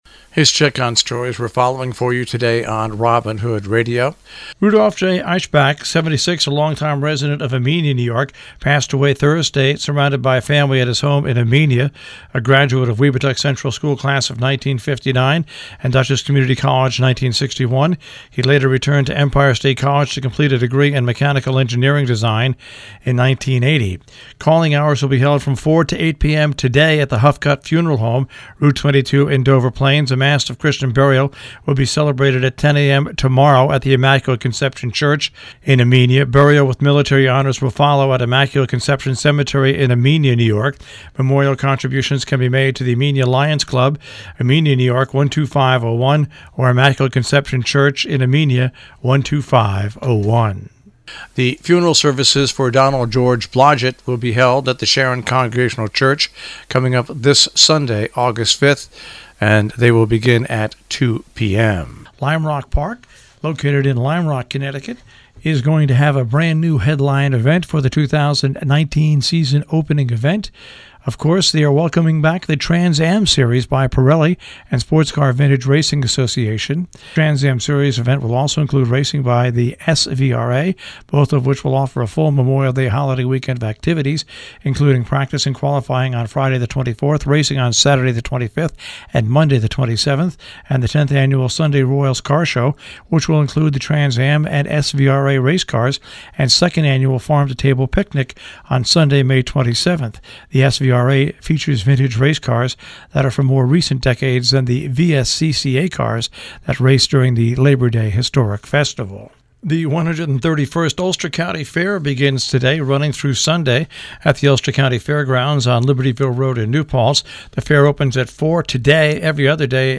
covers news and events in the Tri-State Region on The Breakfast Club on Robin Hood Radio